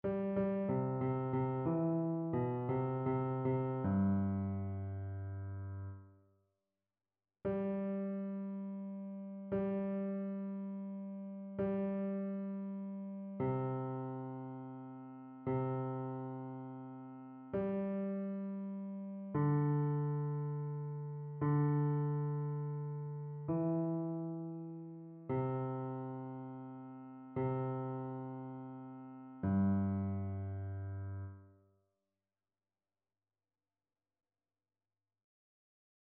Basse
annee-abc-temps-du-careme-veillee-pascale-psaume-29-basse.mp3